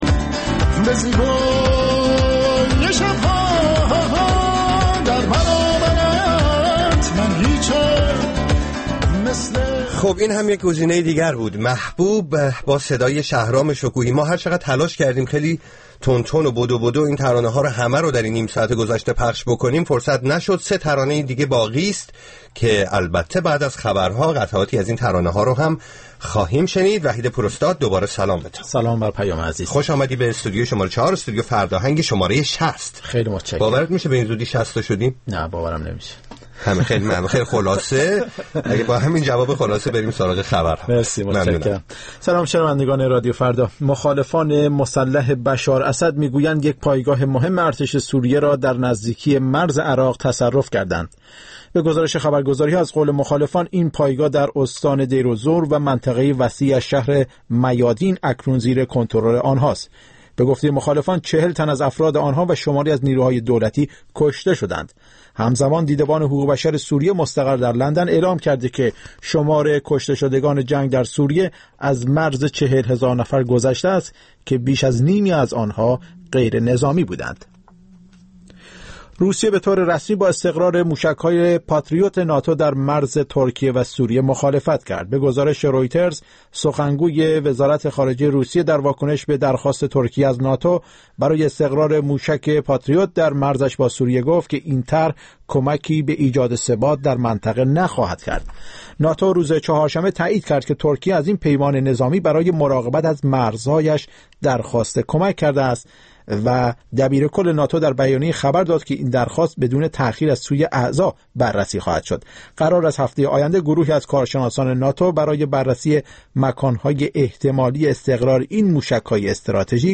برنامه زنده موسیقی